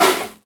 R - Foley 72.wav